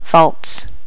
Additional sounds, some clean up but still need to do click removal on the majority.
volts.wav